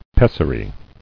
[pes·sa·ry]